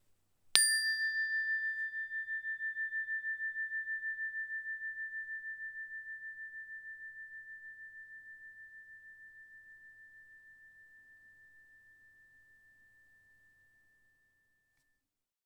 These NINO® instruments have a very cutting “ping” sound. The free-floating bars create a very long sustain.